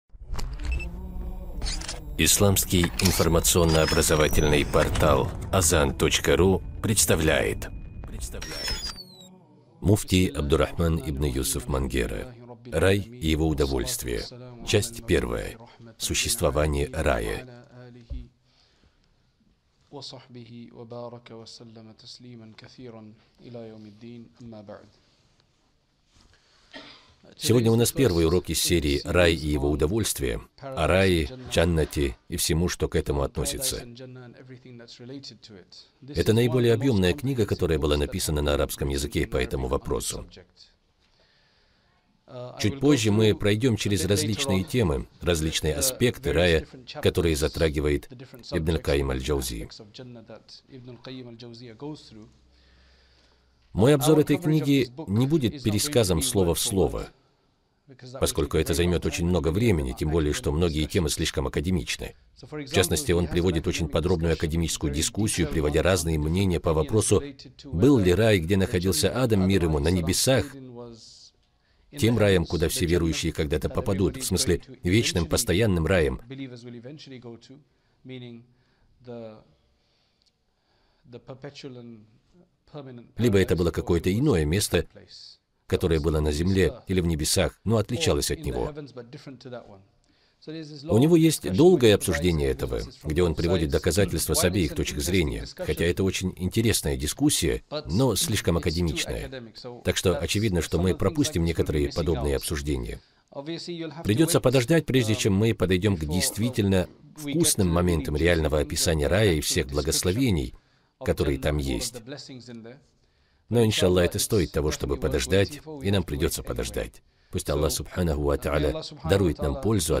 Рай — это место, куда стремятся попасть не только мусульмане, но и люди, далекие от Ислама. В этих уроках шейх объясняет не только великолепие райских благ, но и невероятную силу наслаждения ими, основанную на аятах Корана и хадисах Посланника Аллаха (мир ему и благословение Аллаха).